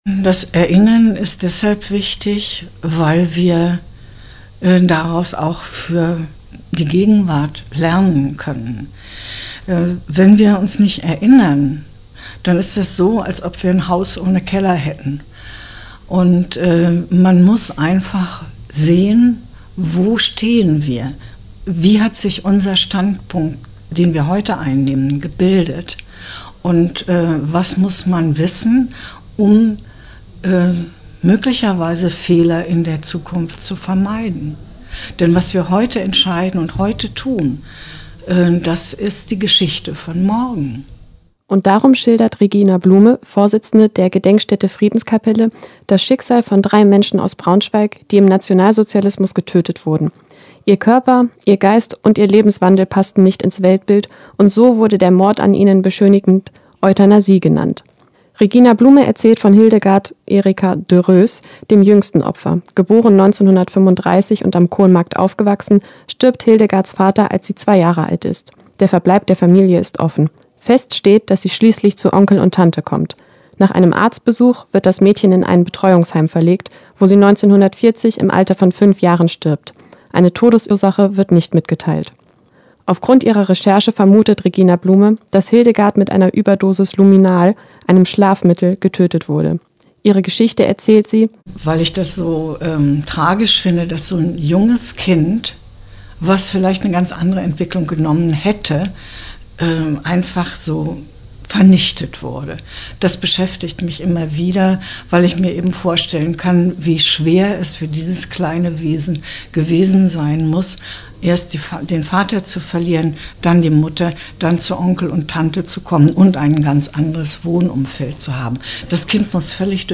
Erinnerung an "Euthanasie"-Opfer: Gedenkfeier auf dem Braunschweiger Stadtfriedhof - Okerwelle 104.6
Seit 2018 erinnert eine Stele auf dem Braunschweiger Stadtfriedhof an die „Euthanasie“-Opfer der Nationalsozialisten. Zum fünften Jahrestag der Einweihung fand am Mittwochabend eine Gedenkfeier statt.